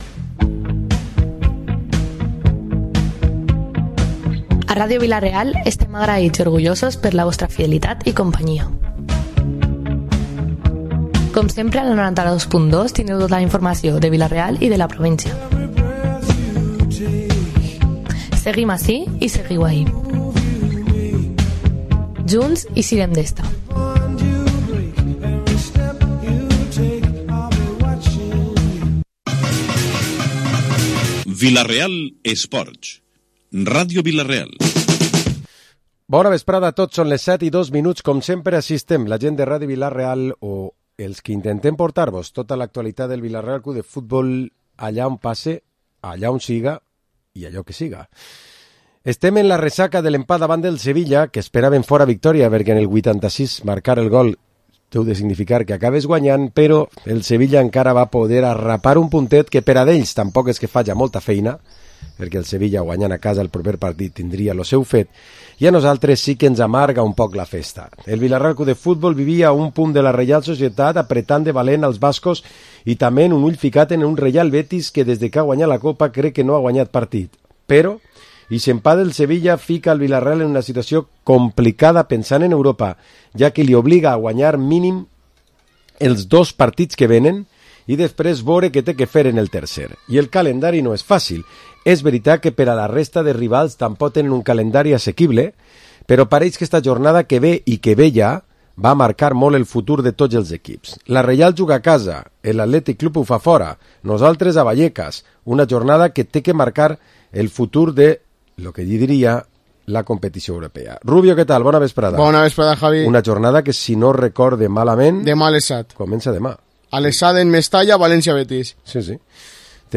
Programa esports tertúlia dilluns 9 de Maig